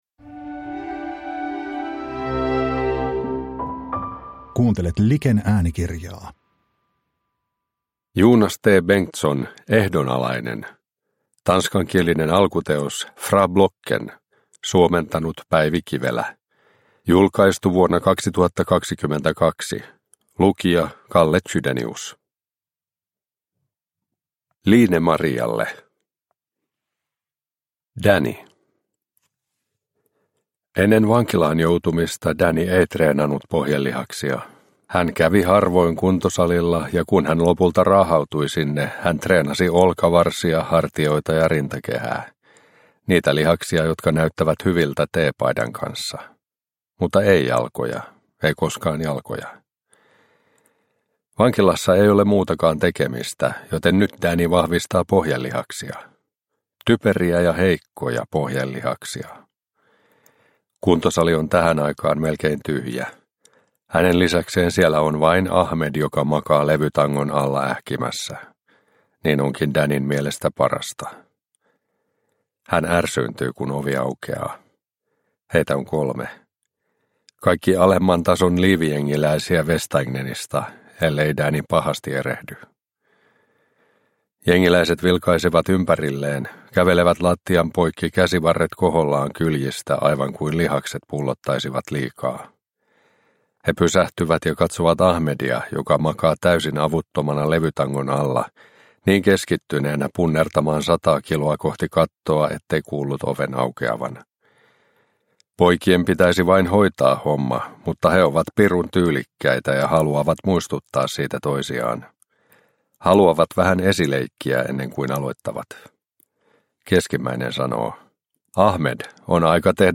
Ehdonalainen – Ljudbok – Laddas ner